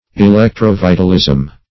Search Result for " electro-vitalism" : The Collaborative International Dictionary of English v.0.48: Electro-vitalism \E*lec`tro-vi"tal*ism\, n. (Physiol.) The theory that the functions of living organisms are dependent upon electricity or a kindred force.